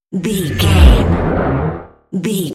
Sci fi big vehicle whoosh
Sound Effects
futuristic
high tech
intense
whoosh